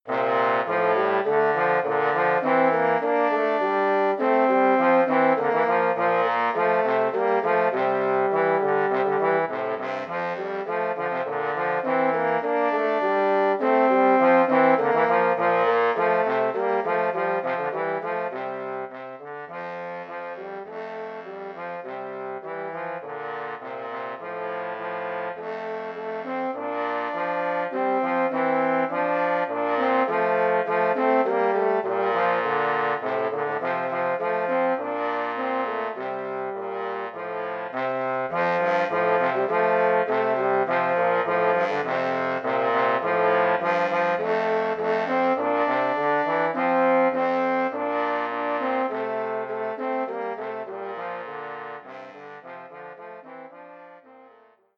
für 2 Posaunen in C